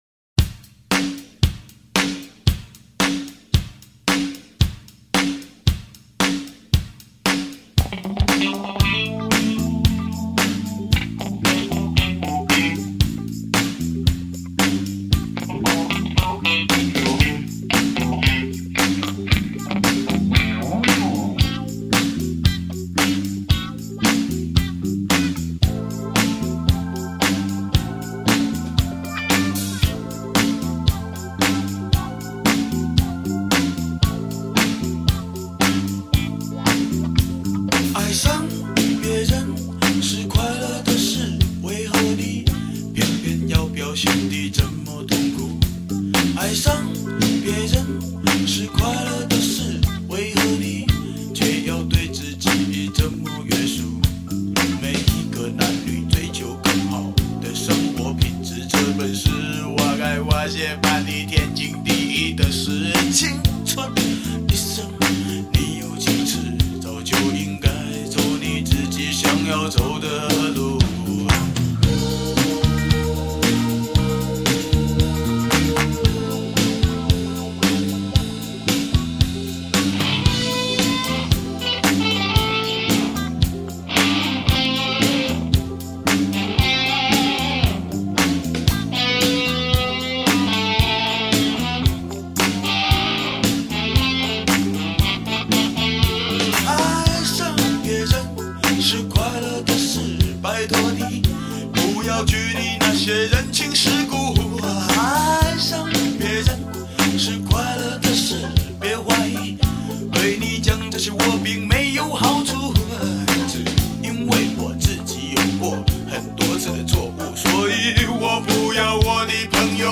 不加过多修饰的歌声将每一个字都深深痛击至心坎里，使每一个节拍都强烈震撼着即将麻痹的灵魂。